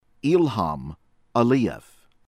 İlham Heydər oğlu Əliyev (magyarosan Ilham Alijev[6] IPA: [il'hɑm æ'lijɛv],
kiejtése; Baku, 1961. december 24.
En-us-Ilham_Aliyev_from_Azerbaijan_pronunciation_(Voice_of_America).ogg